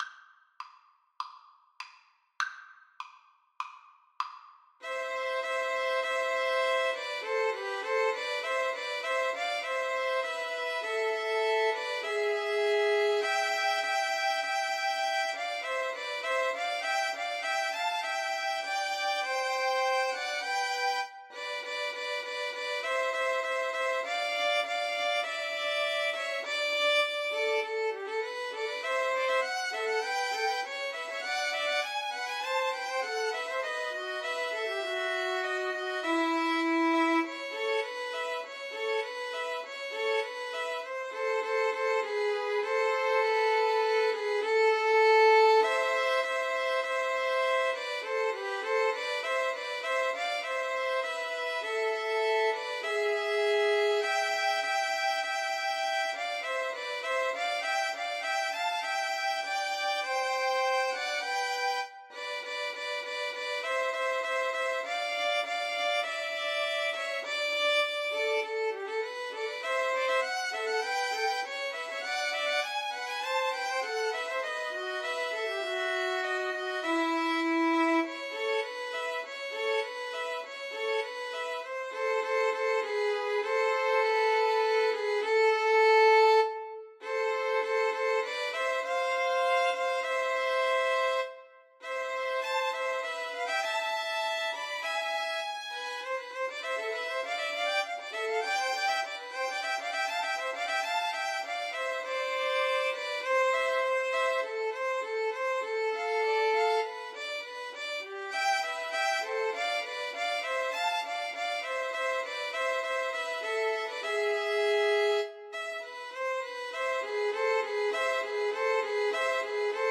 Free Sheet music for Violin Trio
[Moderato]
Classical (View more Classical Violin Trio Music)